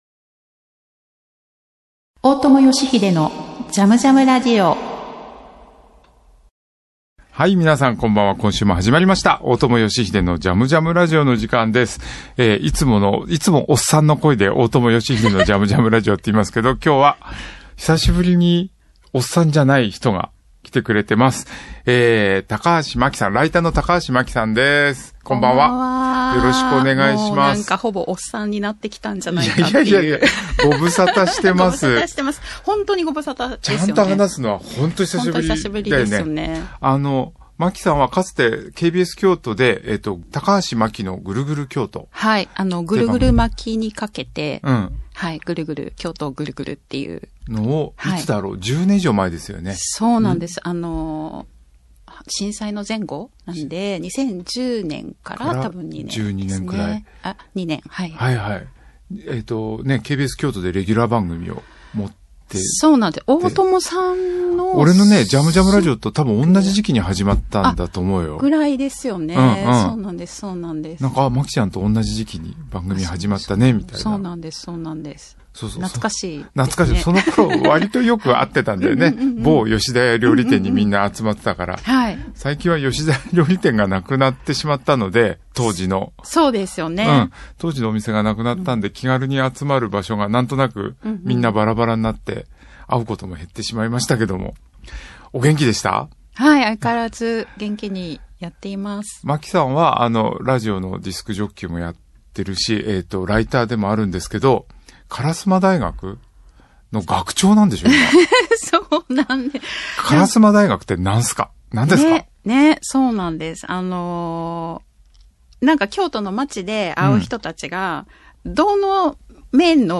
音楽家・大友良英がここでしか聞けないような変わった音楽から昔懐かしい音楽に至るまでのいろんな音楽とゲストを招いてのおしゃべりや、リスナーの皆さんからのリクエストやメッセージにもお答えしていくこの番組ならではのオリジナルなラジオ番組です。